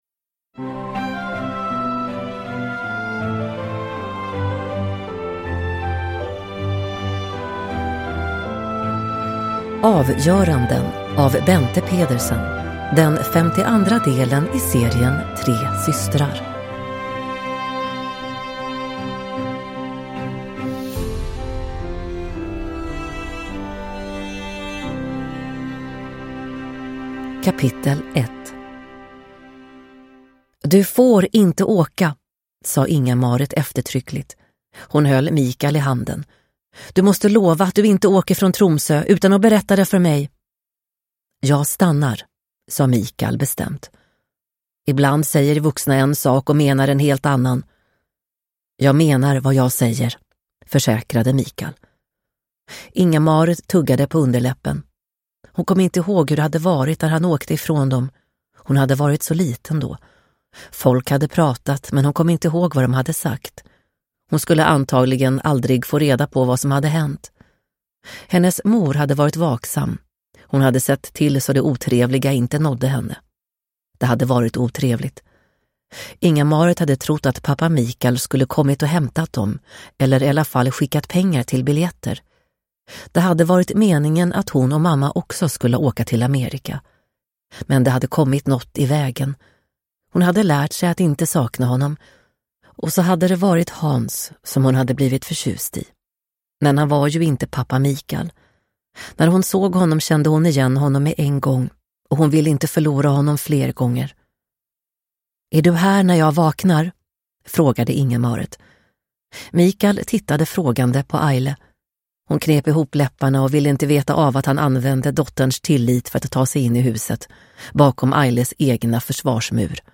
Avgöranden – Ljudbok – Laddas ner